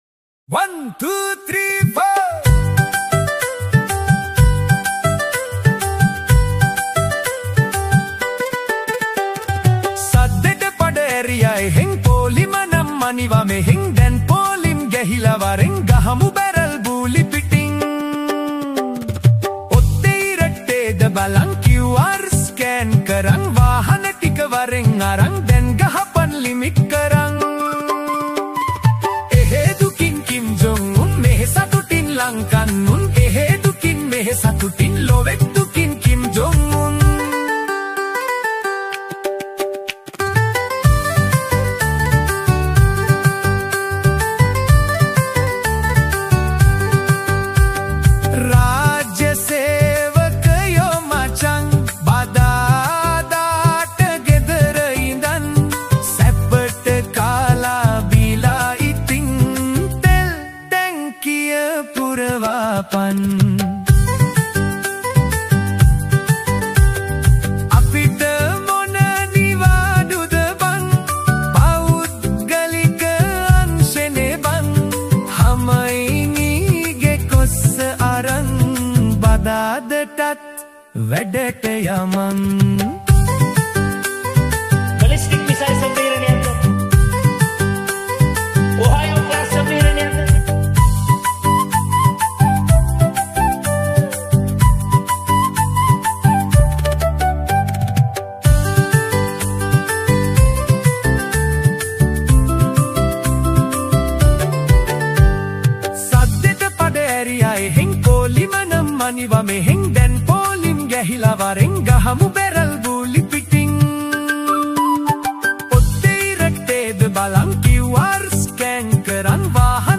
Releted Files Of Sinhal EDM Songs